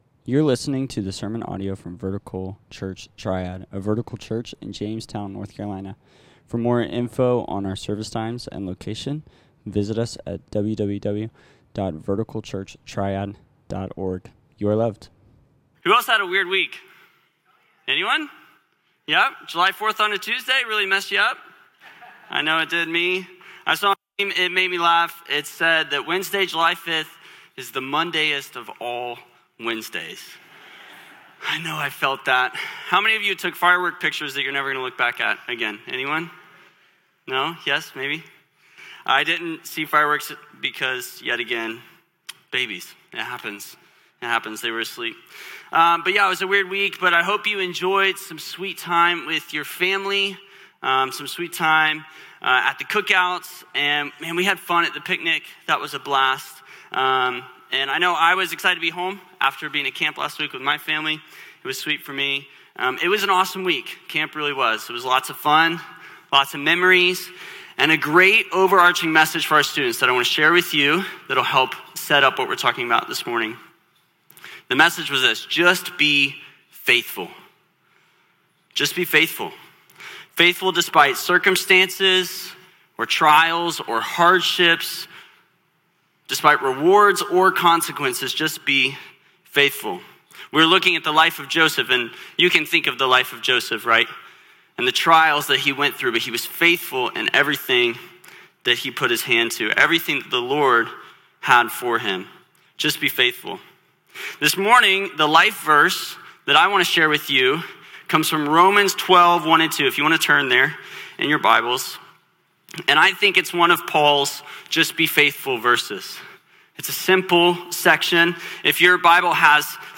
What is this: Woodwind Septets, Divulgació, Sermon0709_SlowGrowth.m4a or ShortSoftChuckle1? Sermon0709_SlowGrowth.m4a